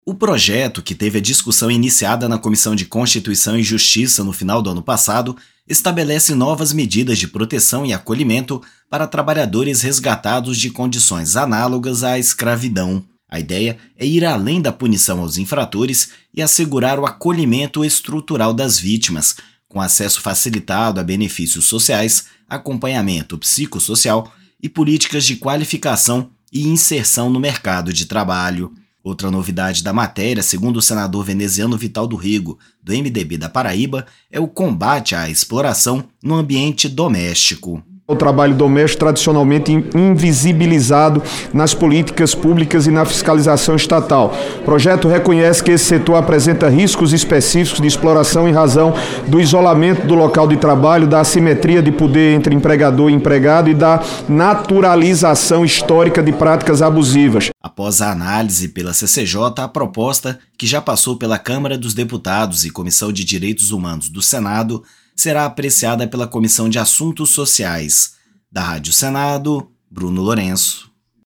O senador Veneziano Vital do Rêgo (MDB-PB) destacou que a proposta busca romper o ciclo de vulnerabilidade que leva à reincidência.